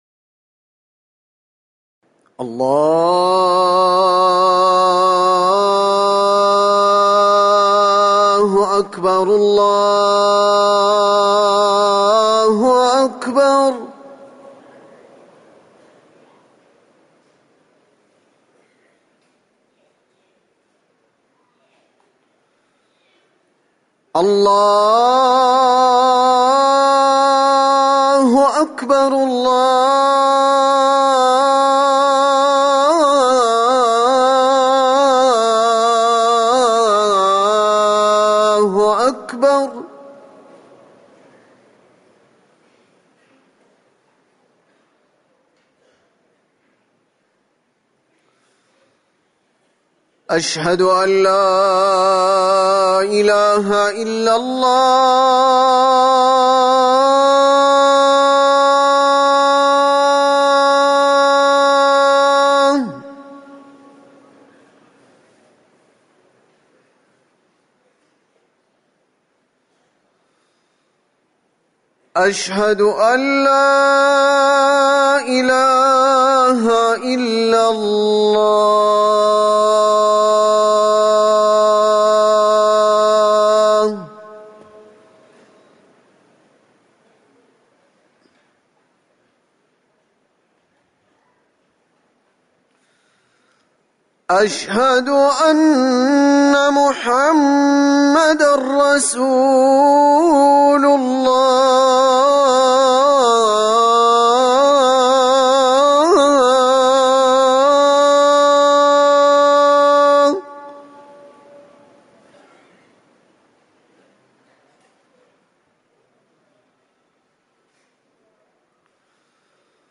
أذان العشاء - الموقع الرسمي لرئاسة الشؤون الدينية بالمسجد النبوي والمسجد الحرام
تاريخ النشر ٢٨ صفر ١٤٤١ هـ المكان: المسجد النبوي الشيخ